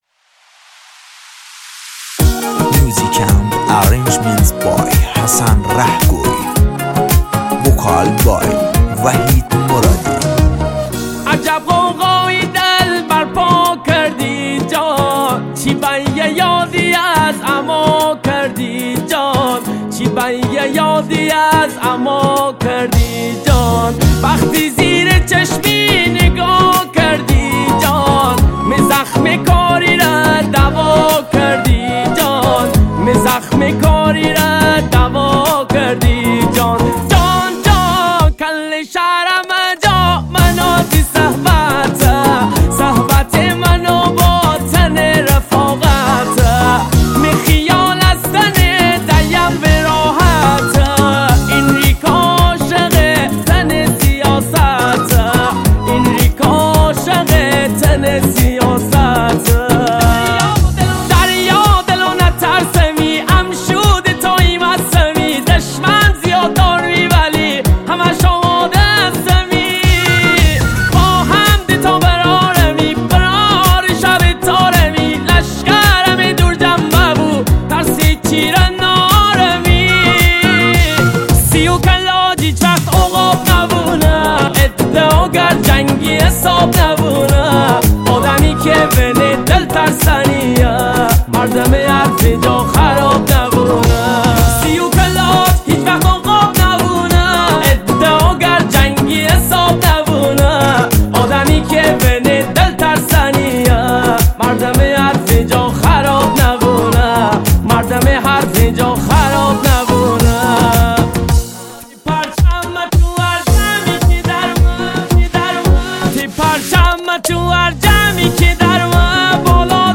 موزیک مازندرانی